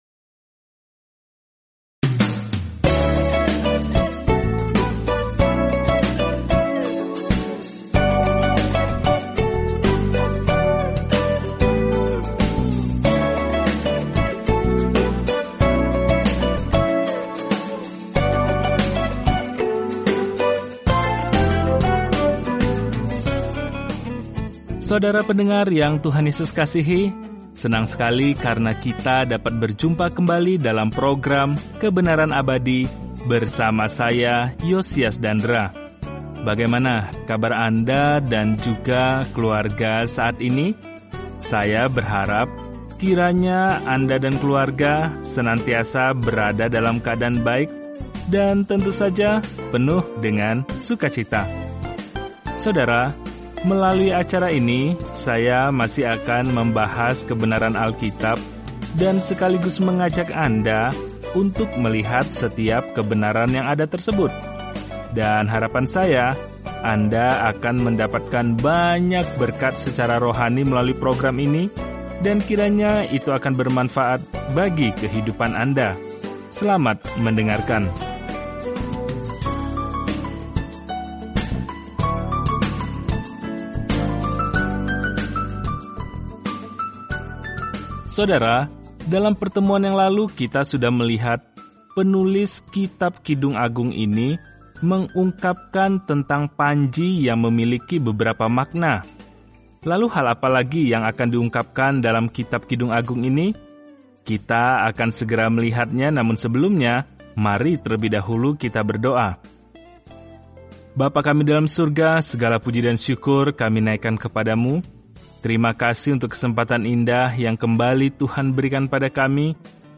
Firman Tuhan, Alkitab Kidung Agung 2:8-11 Hari 7 Mulai Rencana ini Hari 9 Tentang Rencana ini Kidung Agung adalah lagu cinta kecil yang merayakan cinta, hasrat, dan pernikahan dengan perbandingan luas dengan bagaimana Tuhan pertama kali mencintai kita. Perjalanan sehari-hari melalui Kidung Agung sambil mendengarkan studi audio dan membaca ayat-ayat tertentu dari firman Tuhan.